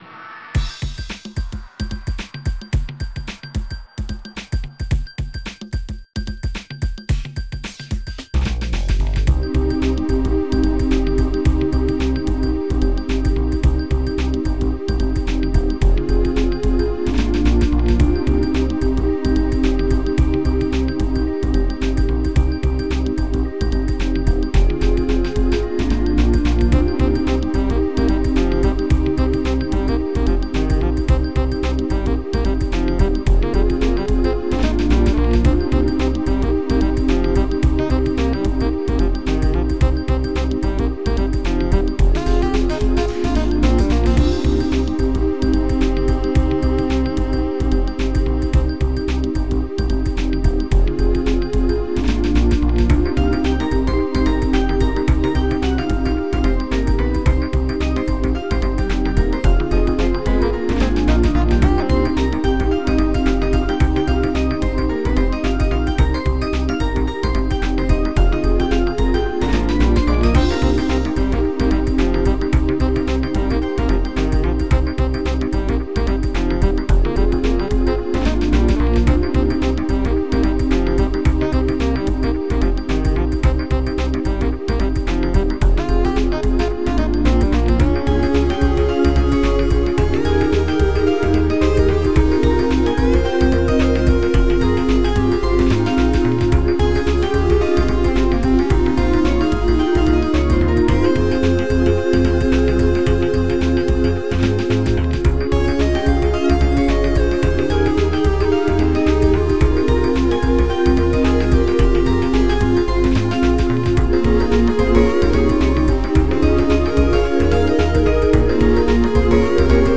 drm: kit 2 - clapsnare
drm: kit 2 - Kick
drm: Bongo 1
drm: crash cymbal
Bass: Clav Bass Lo
brss: lead sax
Lead: Elec Guit
Lead: Piano 1
lead: voice string
lead: electric flute
formatted for maxmimum groovines